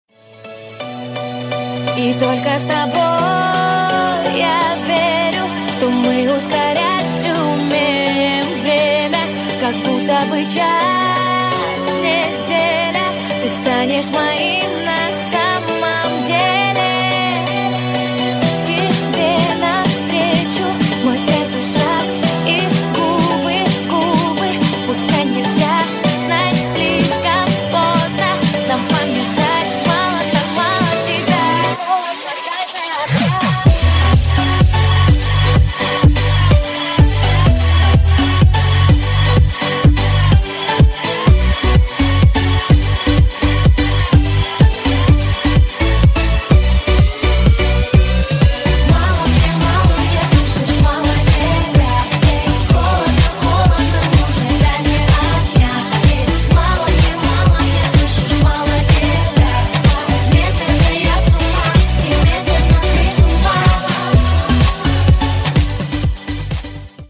• Качество: 128, Stereo
dance
попса